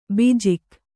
♪ bijik